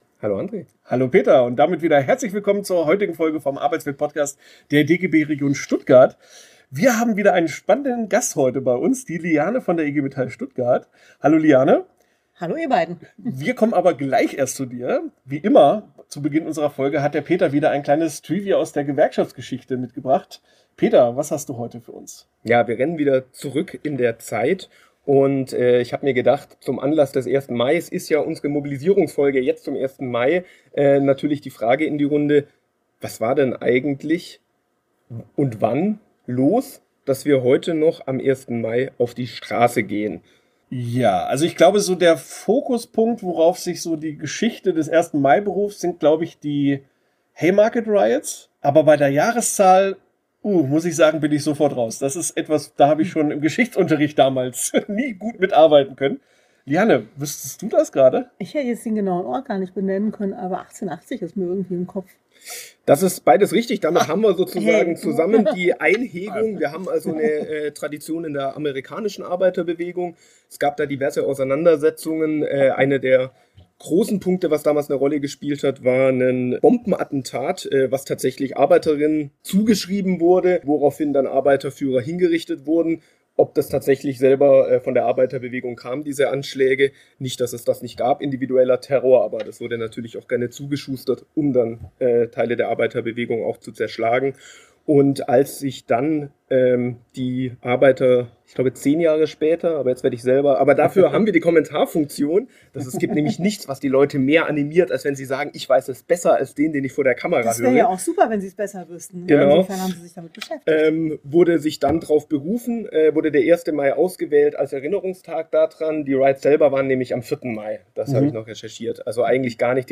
Interview ~ Arbeitswelt Podcast